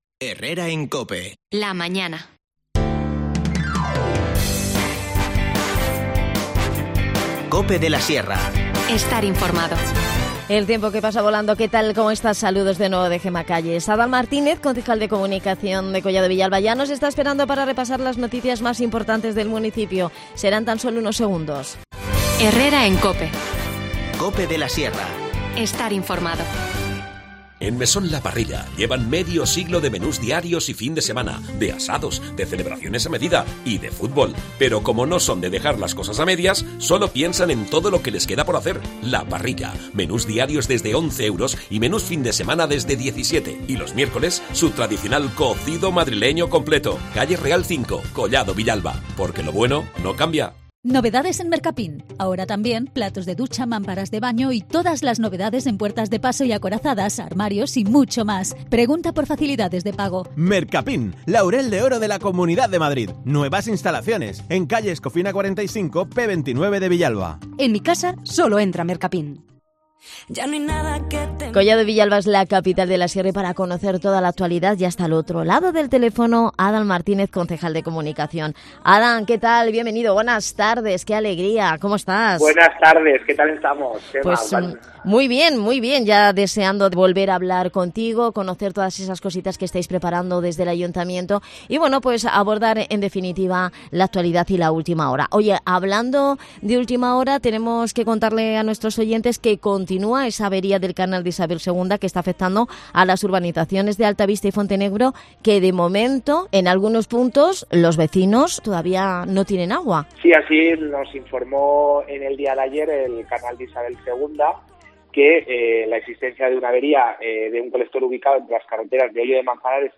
Redacción digital Madrid - Publicado el 30 sep 2021, 13:11 - Actualizado 18 mar 2023, 01:54 2 min lectura Descargar Facebook Twitter Whatsapp Telegram Enviar por email Copiar enlace Repasamos la actualidad de Collado Villalba, Capital de La Sierra, con Adan Martínez, concejal de Comunicación. Entre otros asuntos, ha hablado del corte de suministro de agua que está afectando a los vecinos de Altavista y Dominio de Fontenebro, del refuerzo del Servicio de Orientación para el Empleo y del acuerdo firmado esta semana entre la Agencia Municipal de Colocación e hipermercado Carrefour para gestionar ofertas de trabajo entre los villalbinos. Además, ha adelantado que este jueves en el Pleno se va a debatir para su aprobación una propuesta para destinar 68.000 euros a los afectados por la erupción del volcán en la isla de La Palma.